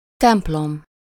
Ääntäminen
IPA: [tɑ̃pl]